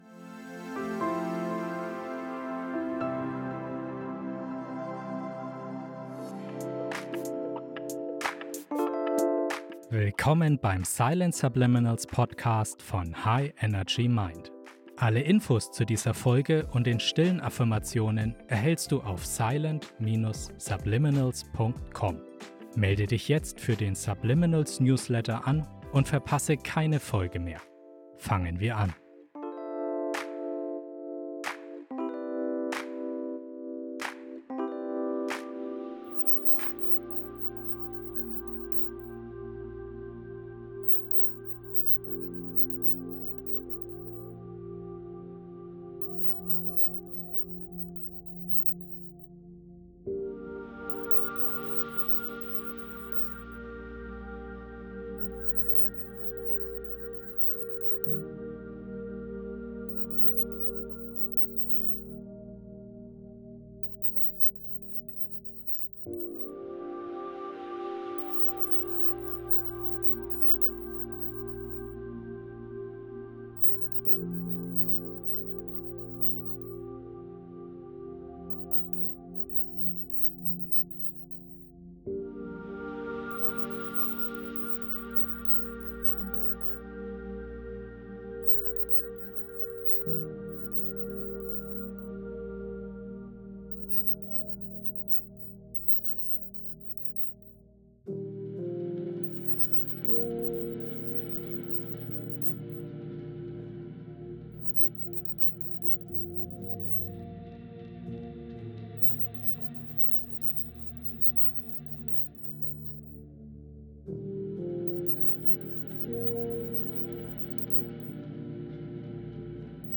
Während du zu beruhigender Musik mit einer Frequenz von 432 Hz entspannst, die bekannt dafür ist, Harmonie und Ausgeglichenheit zu fördern, erfüllen dich unterschwellige Botschaften mit positiven Affirmationen.
Sie sind leise, aber kraftvoll, weil unser Unterbewusstsein sie hört und aufnimmt, auch wenn unser Bewusstsein sie nicht hören kann.